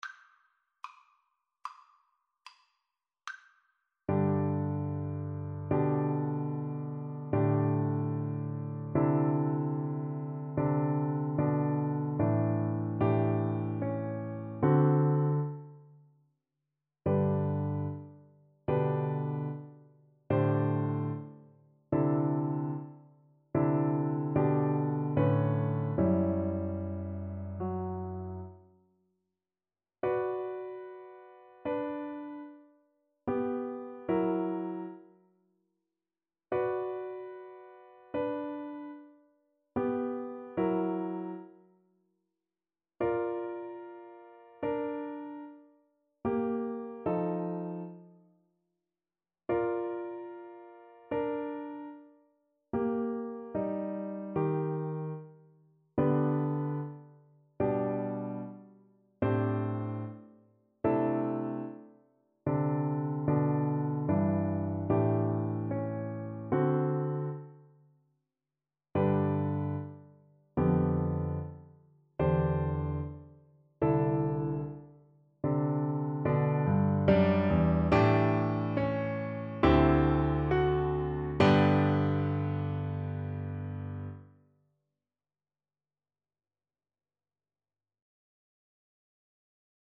Classical (View more Classical Flute Music)